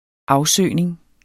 Udtale [ ˈɑwˌsøˀjneŋ ]